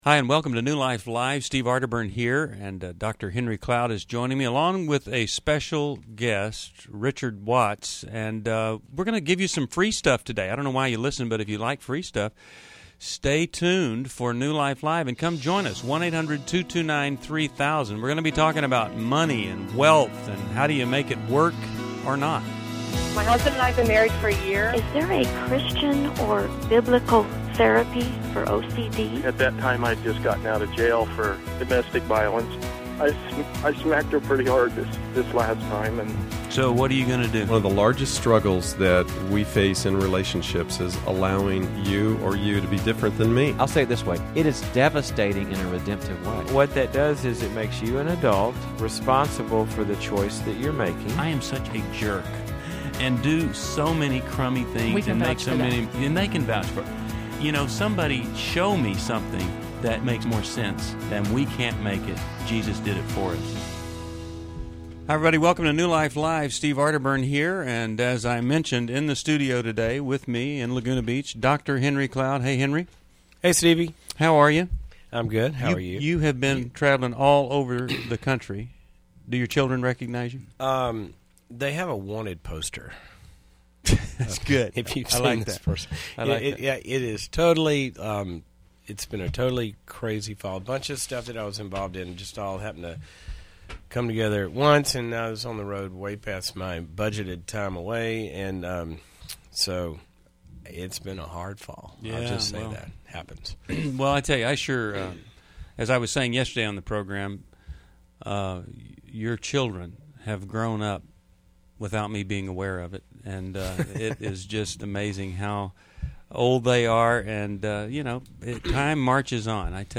Explore finances, giving, and sexual integrity in this episode, featuring caller questions on tithing and family balance.